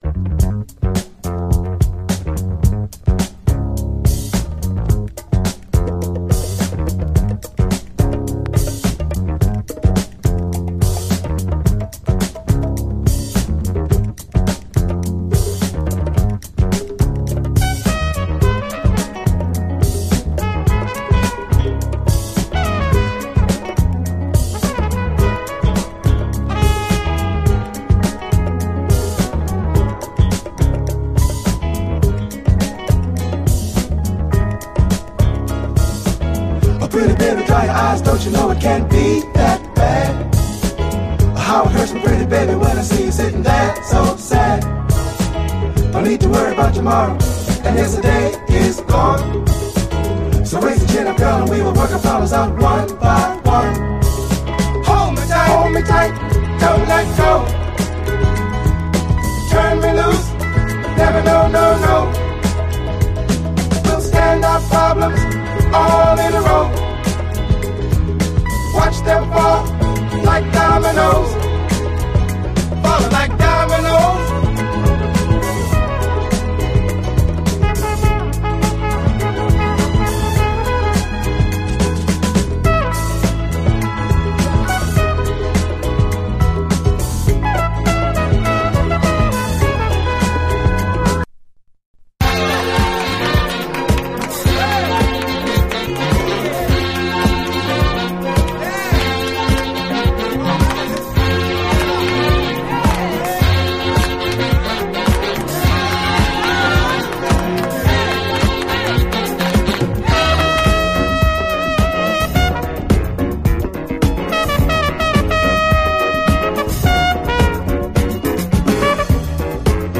SOUL, JAZZ FUNK / SOUL JAZZ, 70's～ SOUL, JAZZ
ありえない浮遊感とタイトなビートで、PETE ROCKらヒップホップ・サイドからも崇められる名盤！